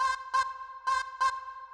K-4 Vocal Loop.wav